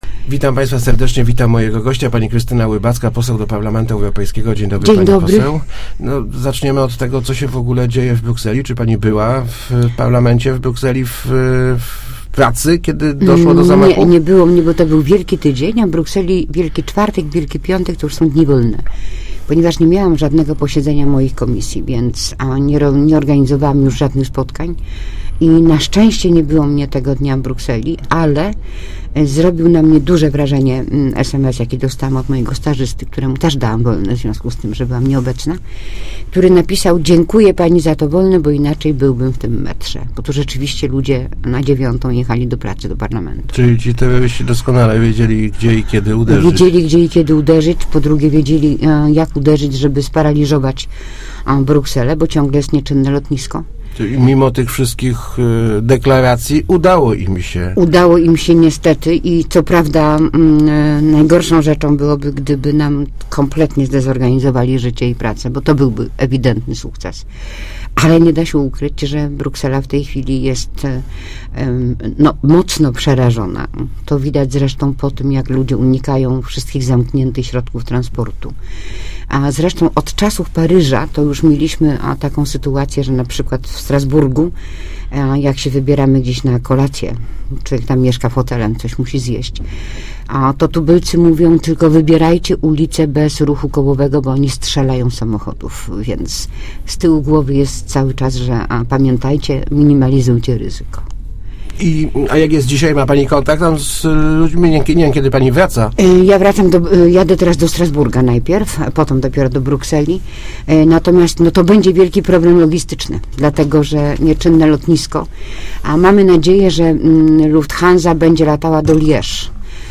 -Da�am nawet wolne mojemu asystentowi, kt�ry p�niej bardzo mi za to podzi�kowa�, poniewa� jak napisa� gdyby pracowa�, to by�by w zaatakowanym metrze - m�wi�a w Rozmowach Elki pos�anka.